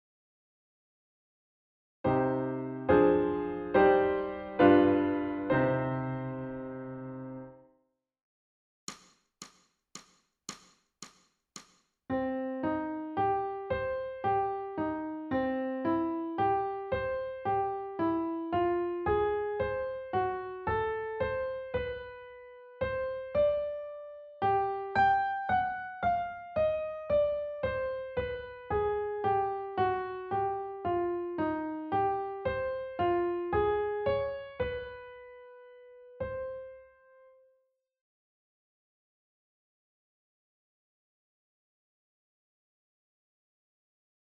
ソルフェージュ 聴音: 2-0-06